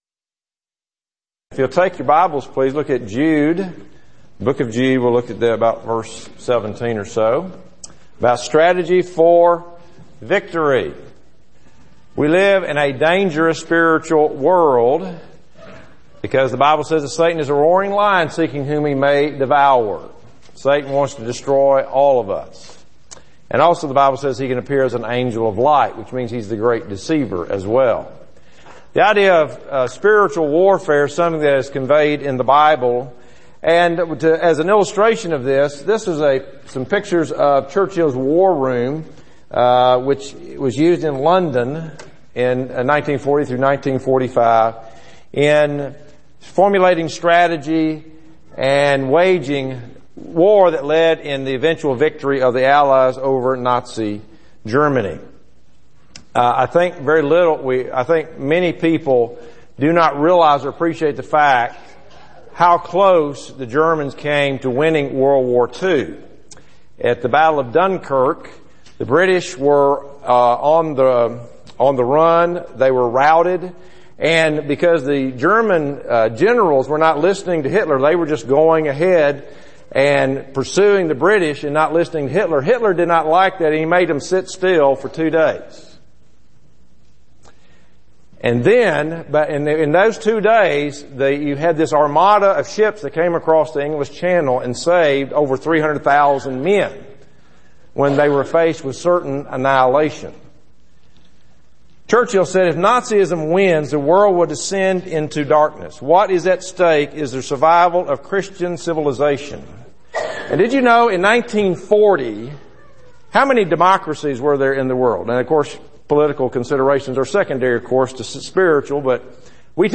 Service: Sun PM Type: Sermon